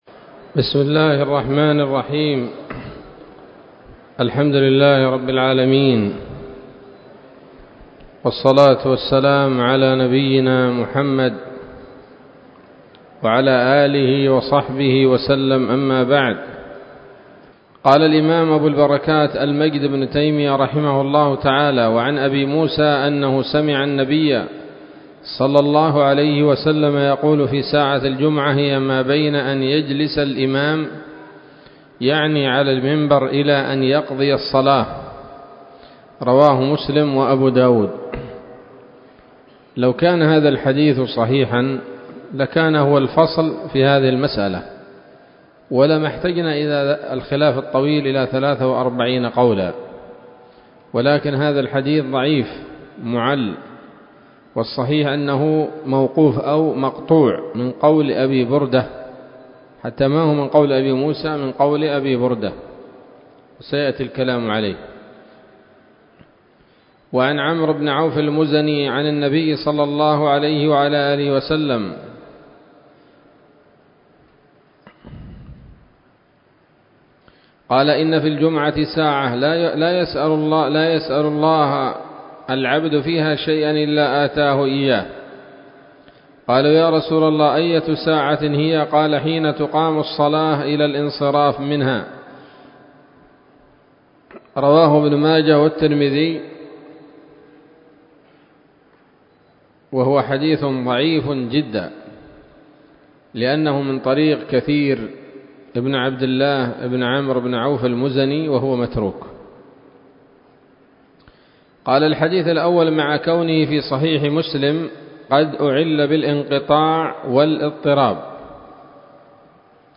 الدرس الثالث عشر من ‌‌‌‌أَبْوَاب الجمعة من نيل الأوطار